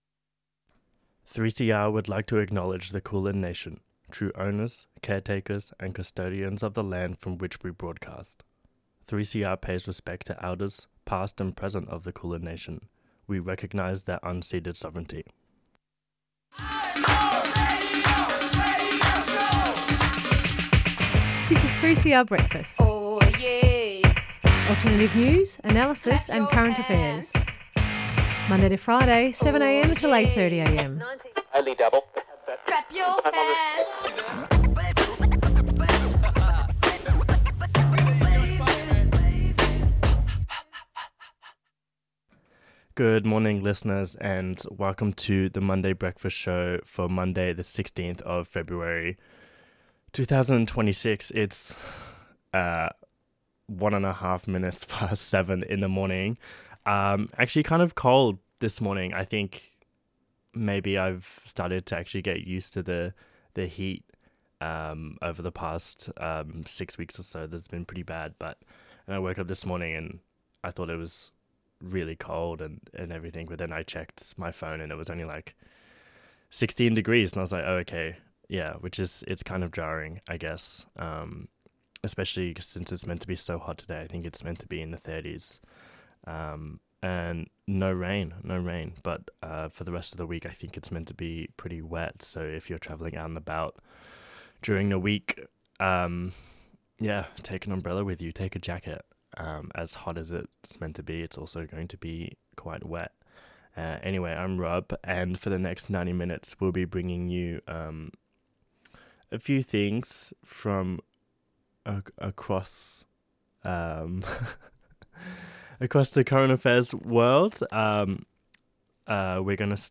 Hello and welcome to the Monday Breakfast show for the 16th of February 2026.